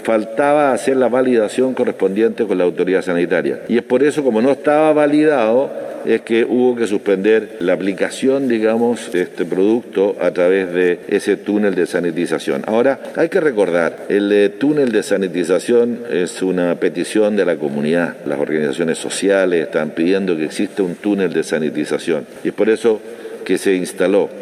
El anuncio lo hizo el intendente de la región de Los Lagos, Harry Jürgensen, quien dijo que el producto químico que era utilizado en los ciudadanos, no estaba validado por la autoridad sanitaria.
cua-intendente-confirma-problema-1.mp3